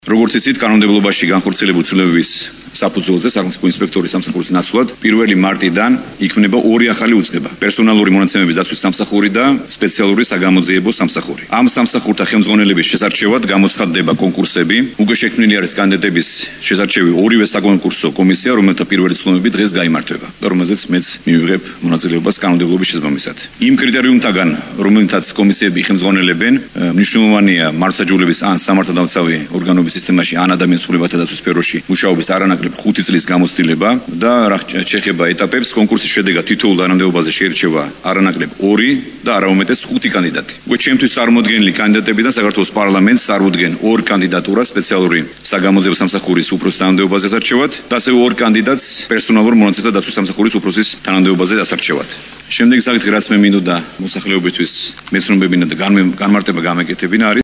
სახელმწიფო ინსპექტორის სამსახურის ნაცვლად 1-ლი მარტიდან ორი ახალი უწყება იქმნება – ამის შესახებ პრემიერ-მინისტრმა ირაკლი ღარიბაშვილმა მთავრობის სხდომაზე განაცხადა.
ირაკლი ღარიბაშვილის ხმა